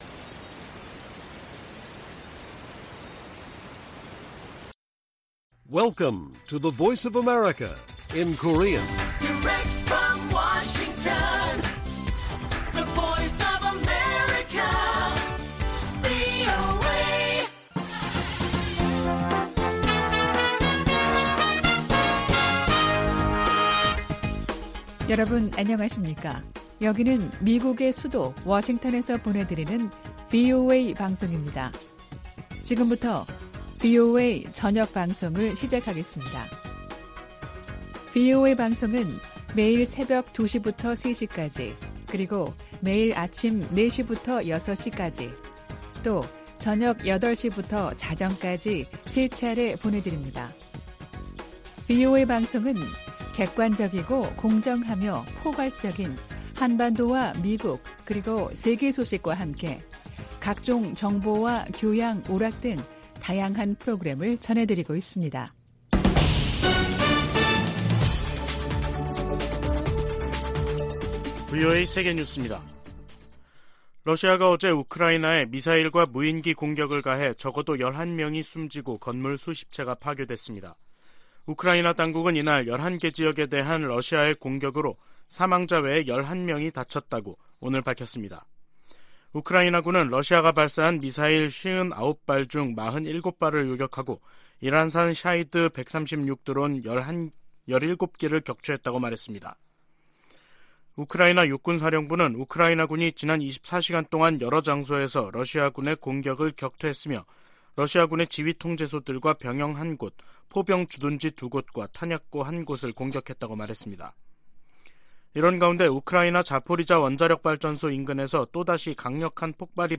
VOA 한국어 간판 뉴스 프로그램 '뉴스 투데이', 2023년 1월 27일 1부 방송입니다. 미국과 한국의 국방장관들이 31일 서울에서 회담을 갖고 대북정책 공조, 미국 확장억제 실행력 강화 등 다양한 동맹 현안들을 논의합니다. 미국 정부가 러시아 군사조직 바그너 그룹을 국제 범죄조직으로 지목하고 현행 제재를 강화했습니다.